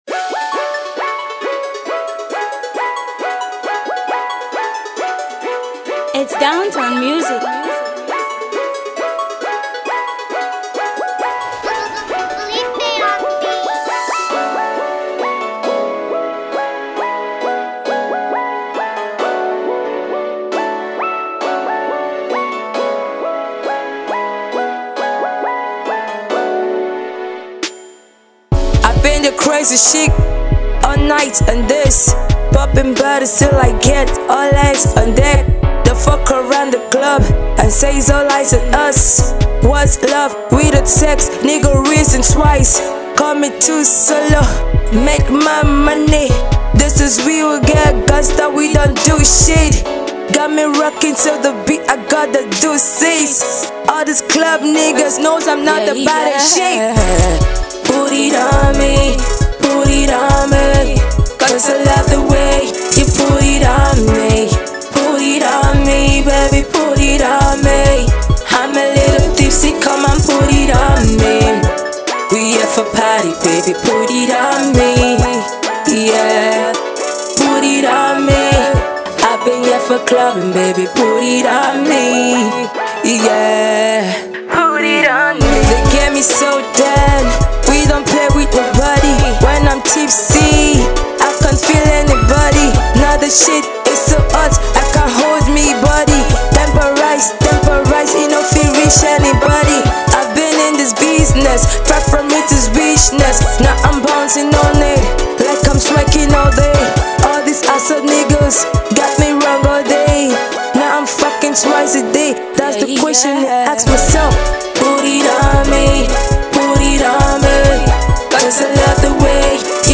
amazing Rap skills
love song